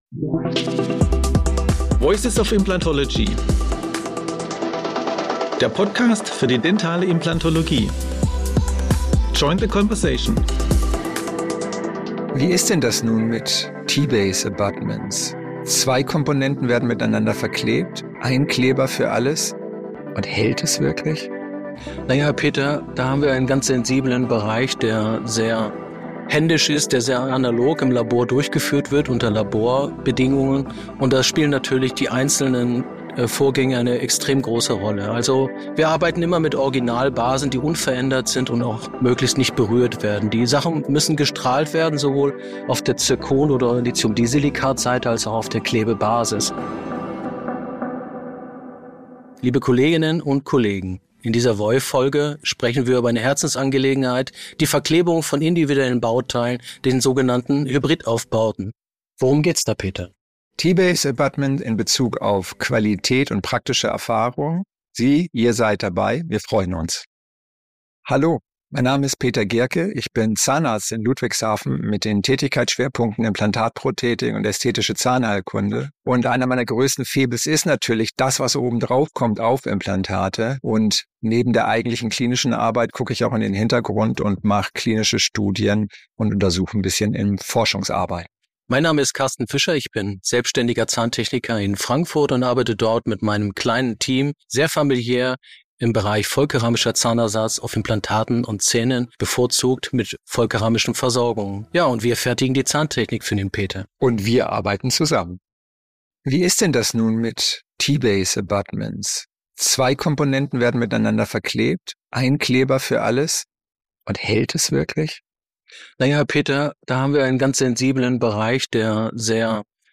Referenten im lockeren Gespräch.